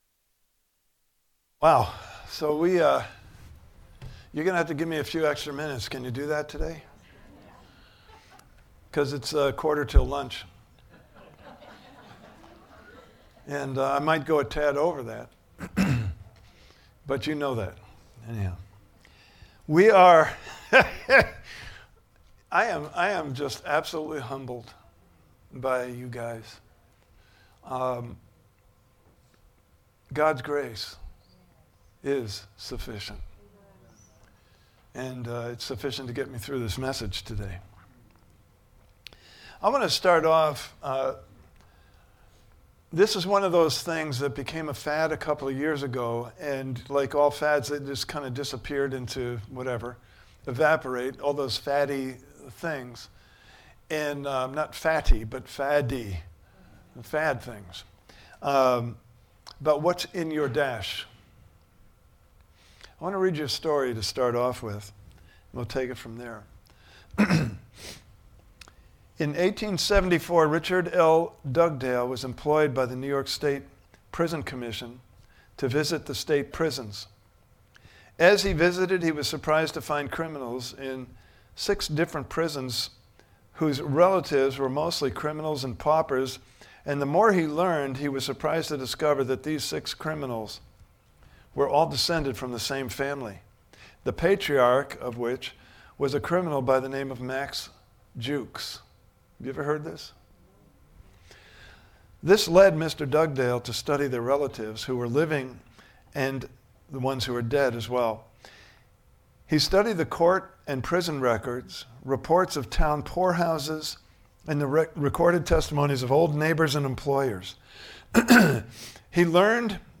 KINGDOM BUSINESS IS ETERNAL Service Type: Sunday Morning Service « Part 1